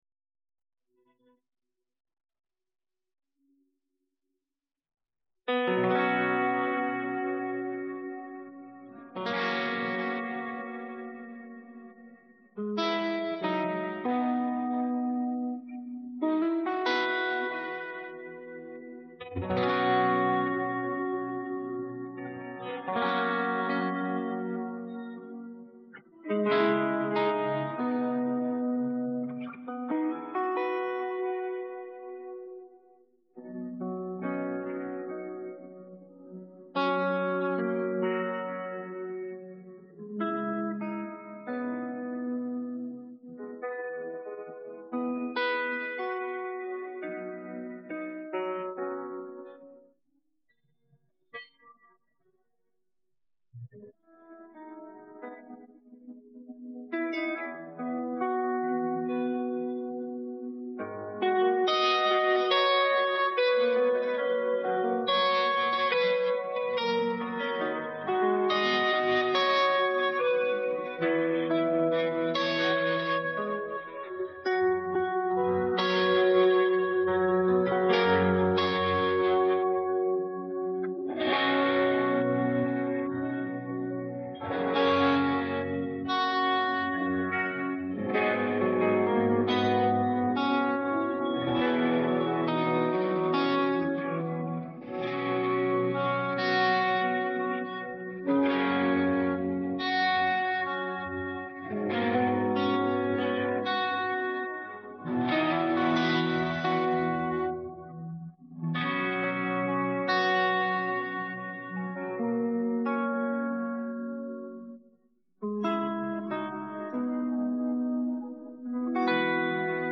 Download Guitar Track
Key of B | BPM 70